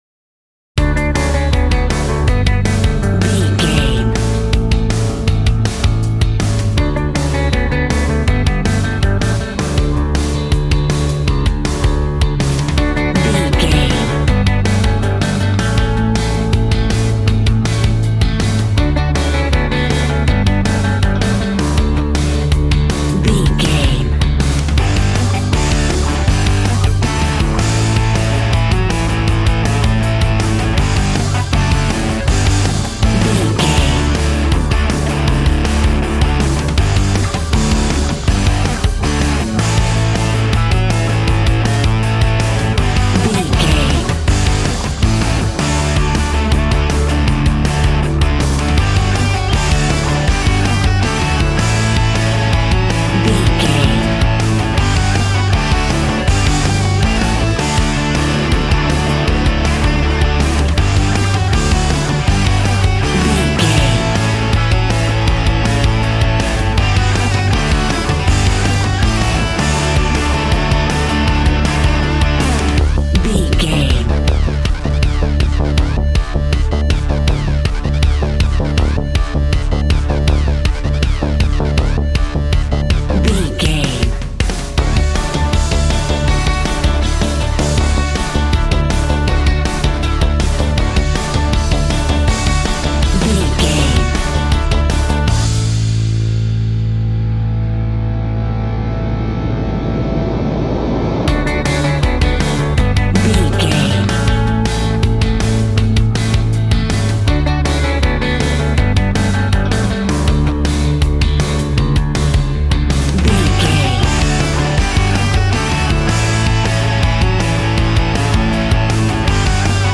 Ionian/Major
D
groovy
happy
electric guitar
bass guitar
drums
piano
organ